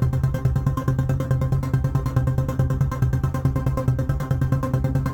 Index of /musicradar/dystopian-drone-samples/Tempo Loops/140bpm
DD_TempoDroneC_140-C.wav